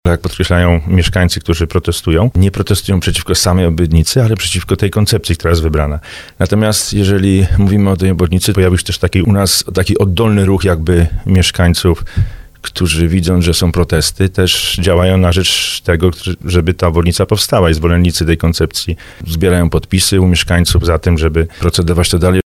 Jeszcze w tym miesiącu ma być wydana decyzja środowiskowa dotycząca budowy obwodnicy Żabna. Mówił o tym w audycji Słowo za Słowo burmistrz Tomasz Kijowski.